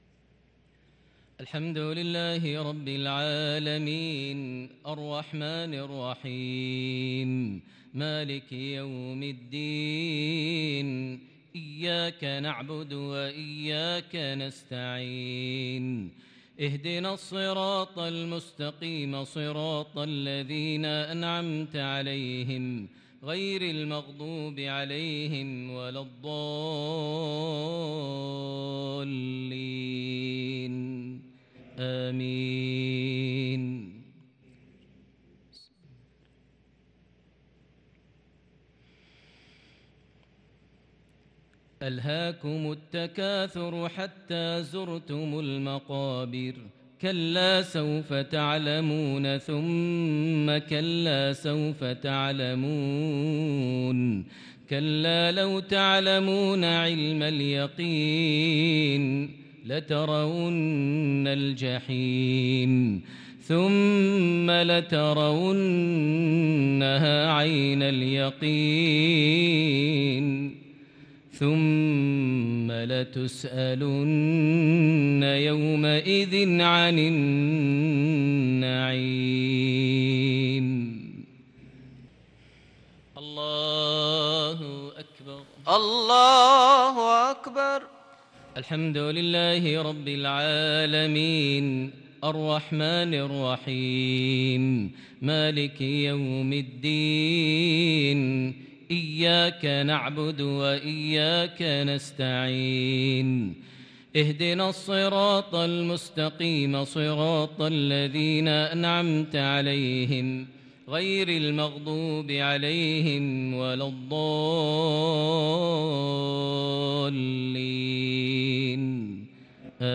صلاة المغرب للقارئ ماهر المعيقلي 1 ذو القعدة 1443 هـ
تِلَاوَات الْحَرَمَيْن .